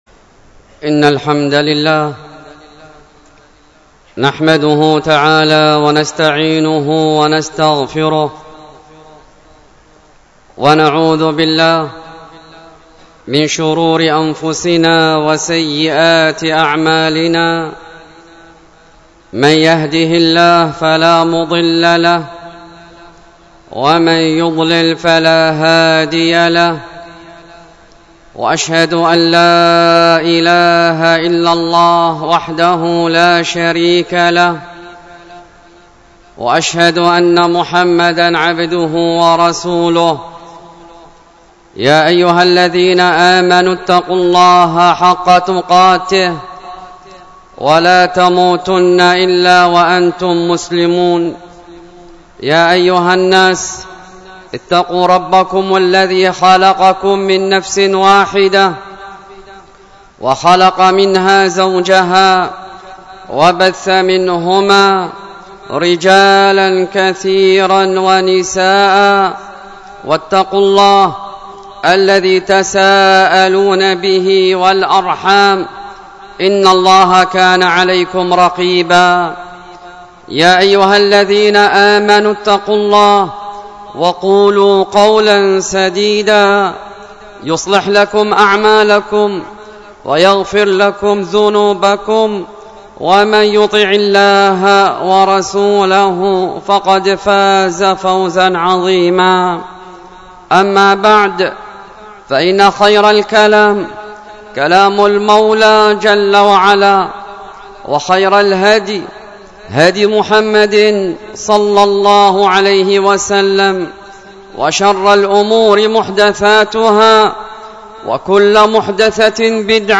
الخطبة بعنوان خطر صحبة الأشرار، والتي كانت بدار الحديث بالفيوش